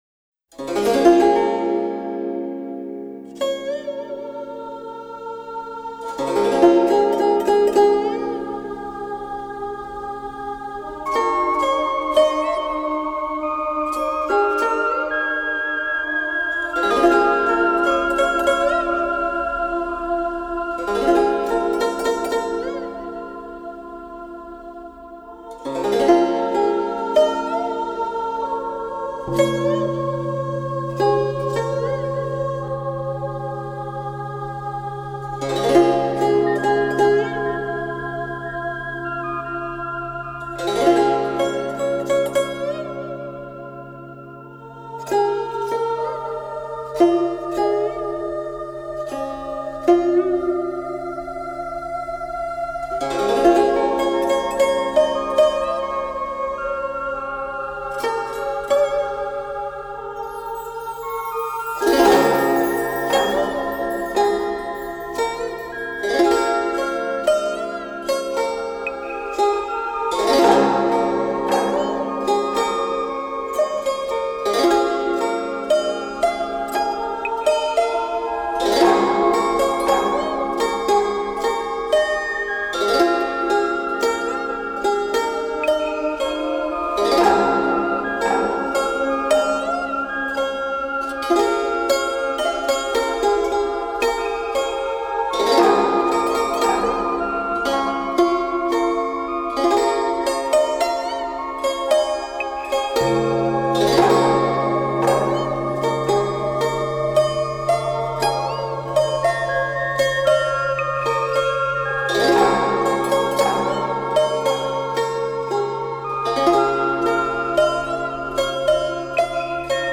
New Age, Ambient, Electronic, World Music